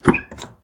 openCSqueaky.ogg